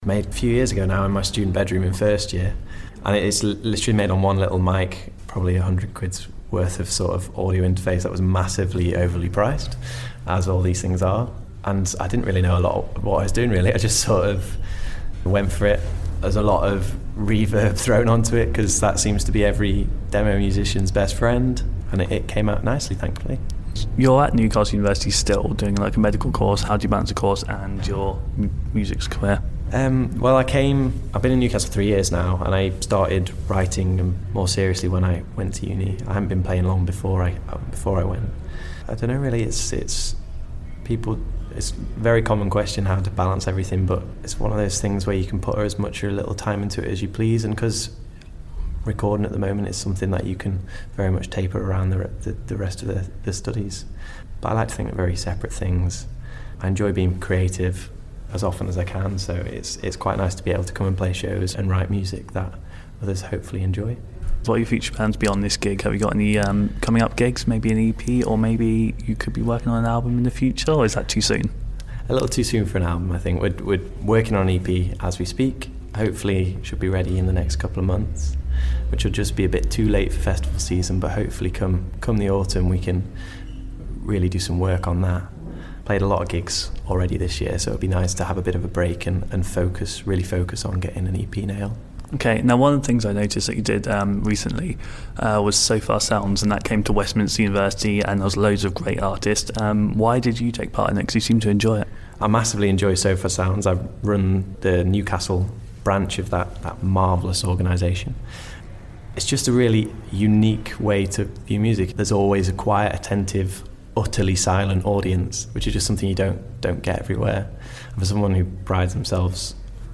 in a lovely little pub in Islington back in April